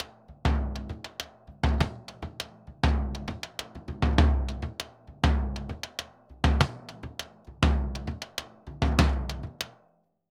Bombo_Candombe_100_2.wav